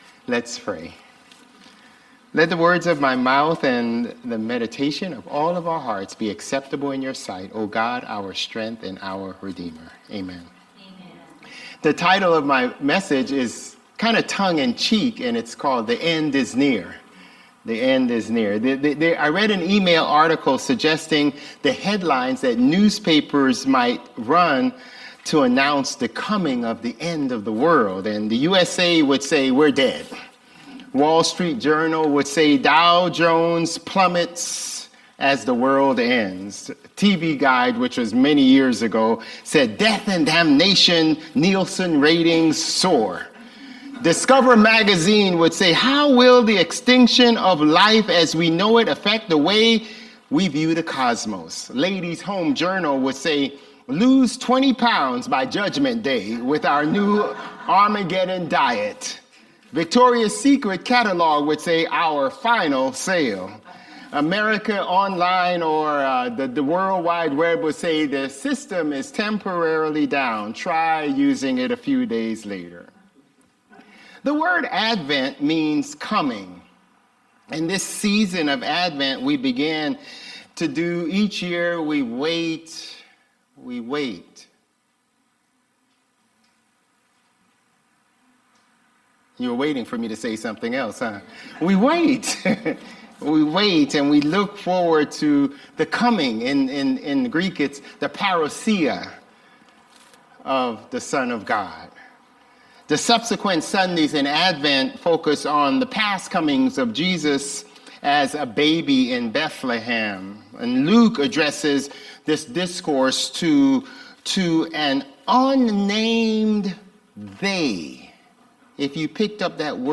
Sermons | Bethel Lutheran Church
December 8 Worship